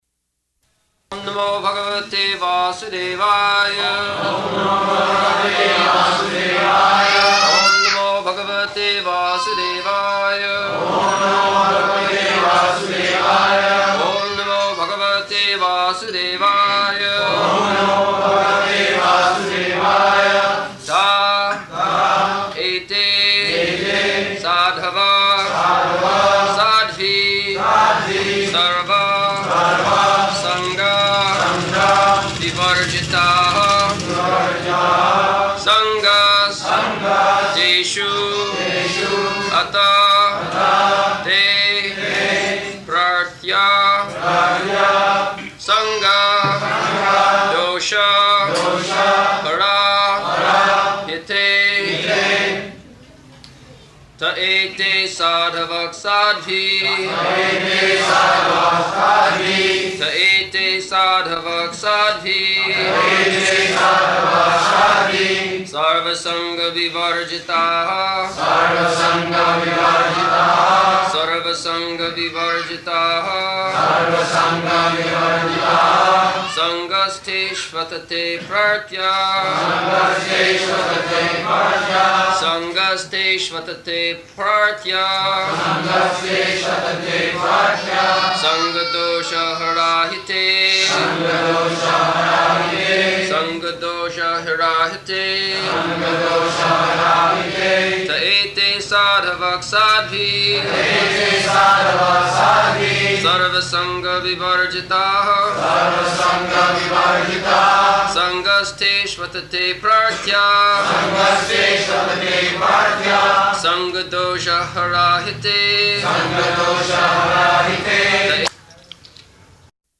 -- Type: Srimad-Bhagavatam Dated: November 24th 1974 Location: Bombay Audio file
[Prabhupāda and devotees repeat]